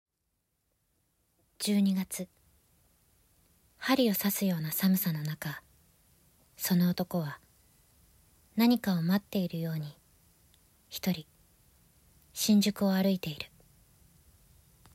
ボイスサンプル
ナレーションA